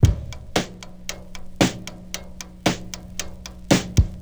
• 114 Bpm Breakbeat G Key.wav
Free breakbeat - kick tuned to the G note. Loudest frequency: 1527Hz
114-bpm-breakbeat-g-key-UA7.wav